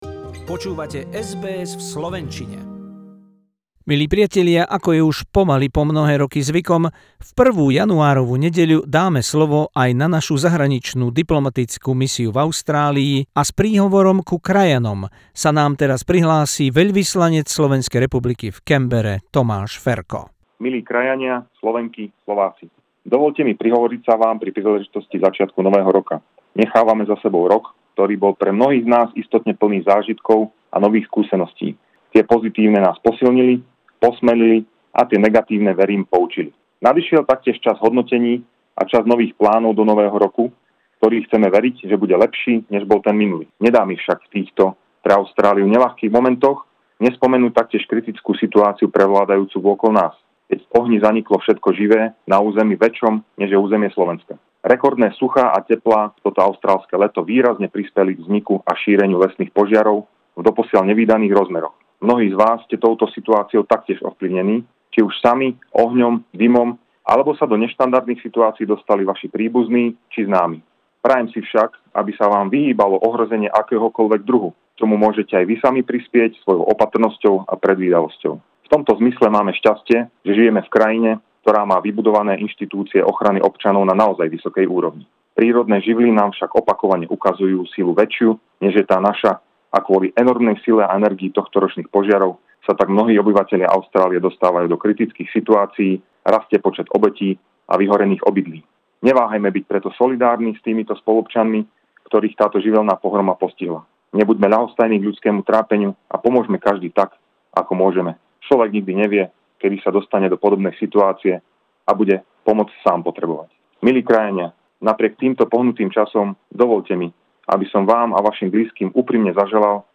Traditional New Year message of the Slovak Ambassador in Canberra Tomas Ferko to Slovak community in Australia.